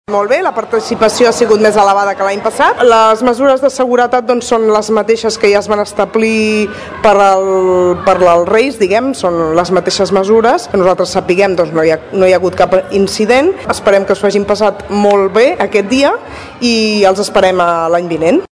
En declaracions a aquesta emissora, la regidora de Festes, Sílvia Català, es mostrava molt satisfeta amb la participació de la gent en la rua d’enguany. A més, destacava que la rua es desenvolupés sense incidències.